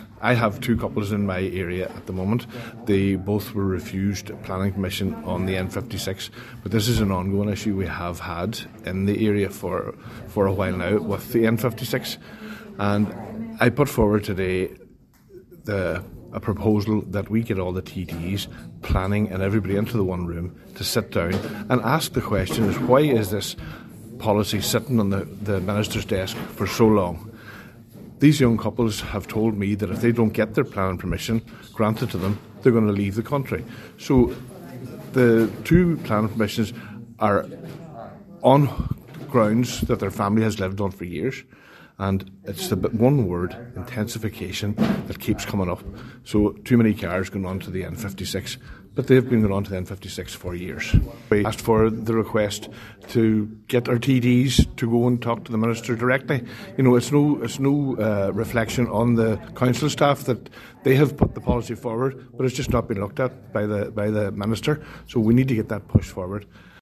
Planning on the N56 was at the top of the agenda at this morning’s Glenties Municipal District meeting.
Cllr Brian Carr told the meeting that he knows of couples who were denied planning permission on family-owned land: